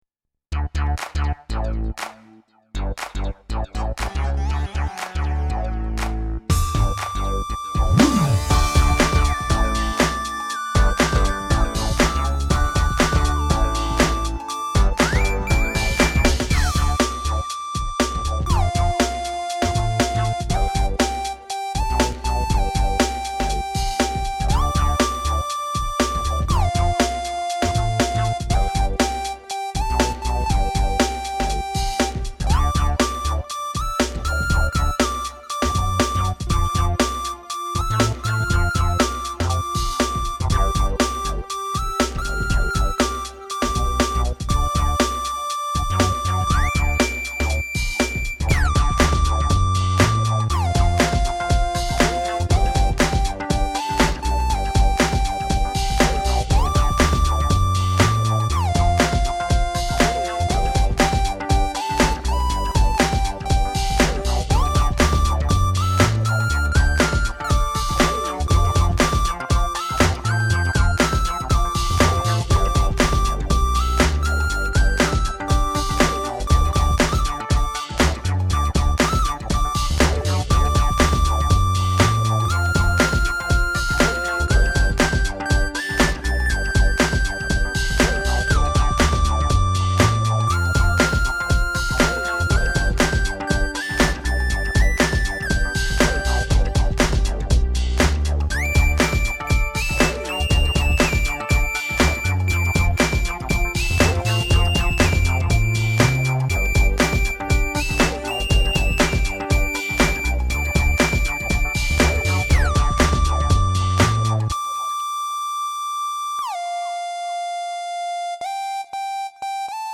Besides that though, the end piece is an original composition I originally recorded a few weeks ago... which I reproduced in part while filming this.
When you played just the instrumental version of the song, it reminded me of the music from Metroid.
When you added the drumbeat, it sounded like this one song they play in the background of the movie Snatch.